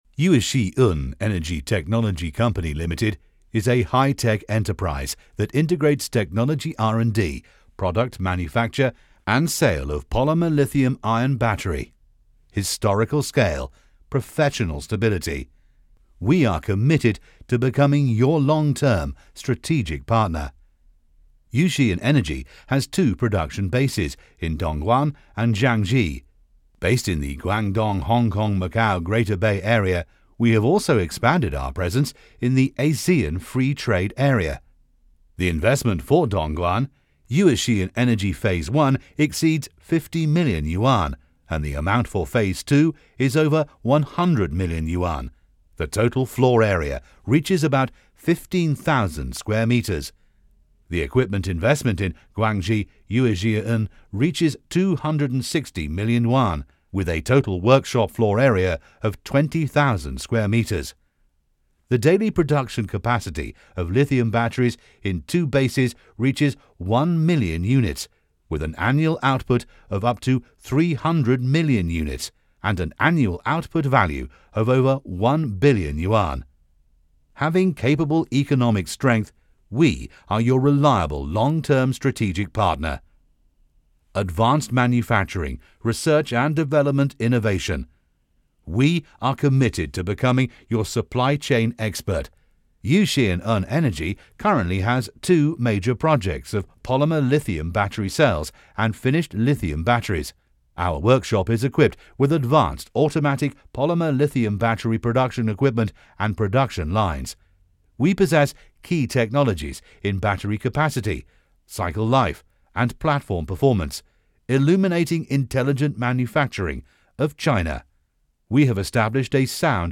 英式英语配音 BBC知名主播 大气申请 宣传片
成熟厚重 自然诉说 大气浑厚
拥有专业的声音工作室。
w108-新能源宣传片.mp3